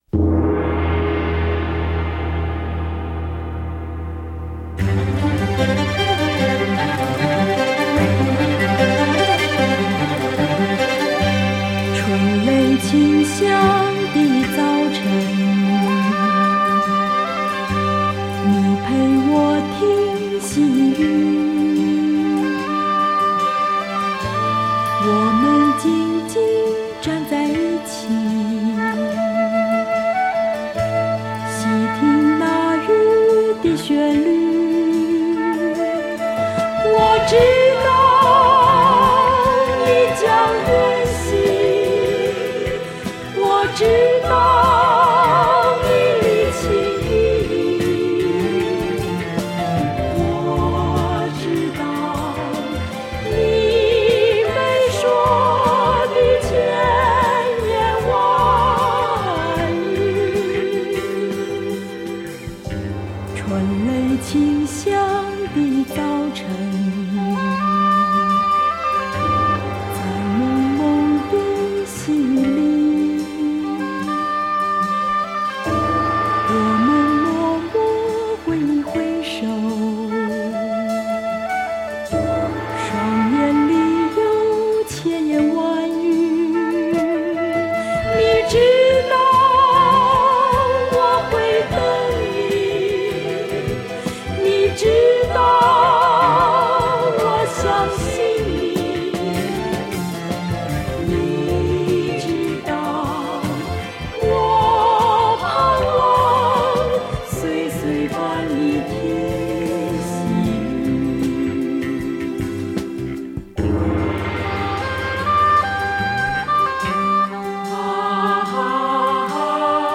三重唱